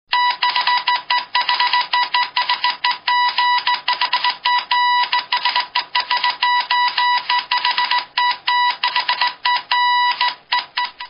Код Морзе (Morse code)
Отличного качества, без посторонних шумов.